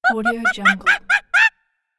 Monkey Téléchargement d'Effet Sonore
Monkey Bouton sonore